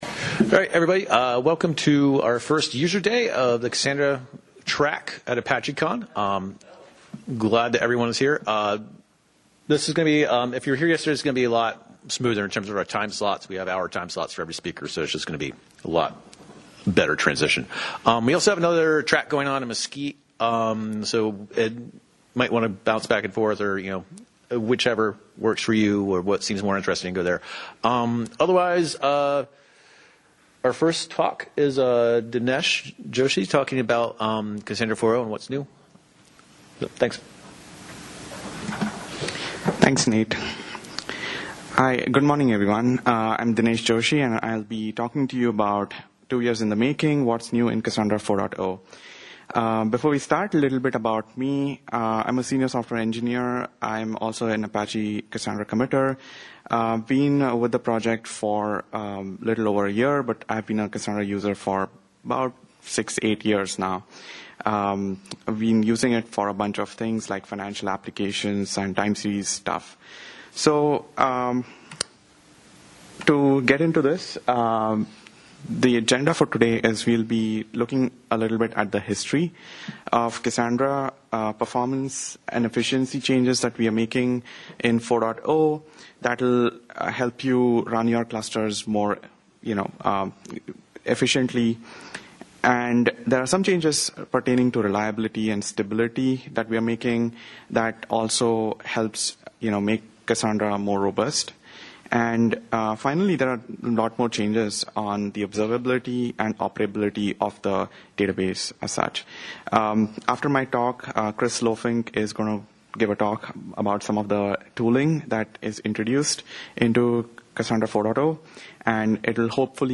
At almost two years in the making Apache Cassandra 4.0 is almost here. With a focus on performance and stability, it is full of interesting features. This talk takes you through a tour of the new features and performance improvements.